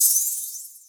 [openhat] (9) tm88.wav